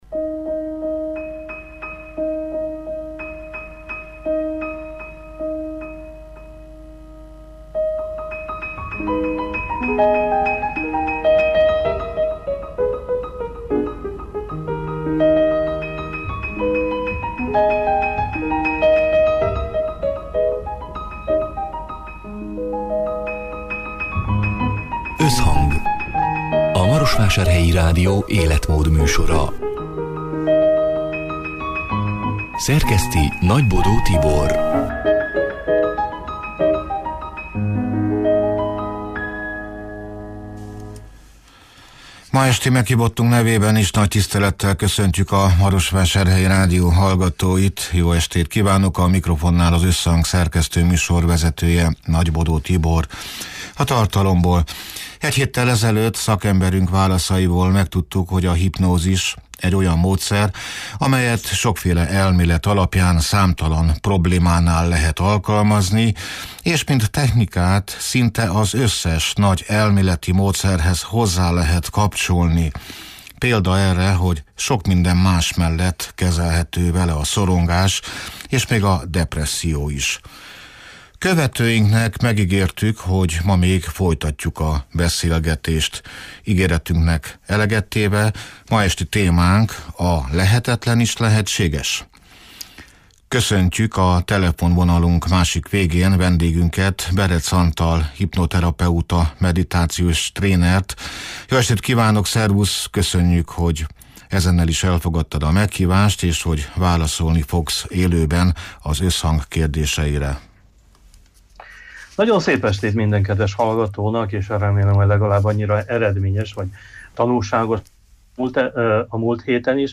(elhangzott: 2025. március 26-án, szerdán délután hat órától élőben)